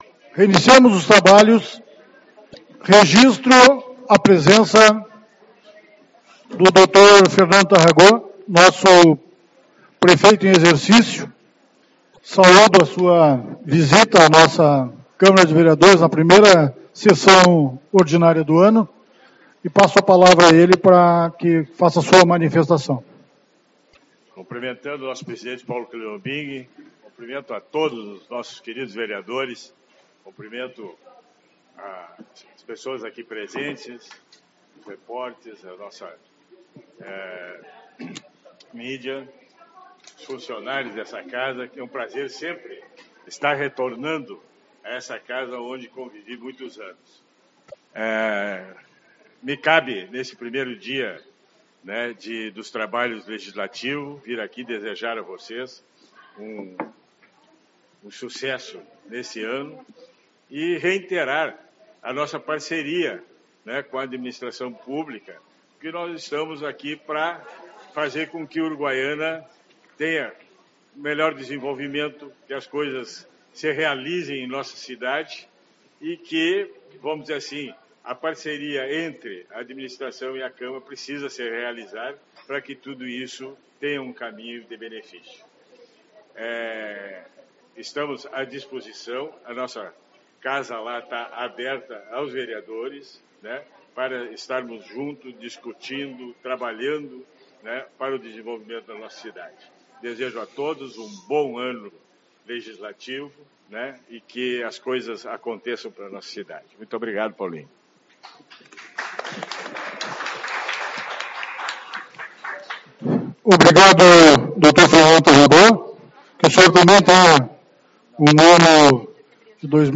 03/02 - Reunião Ordinária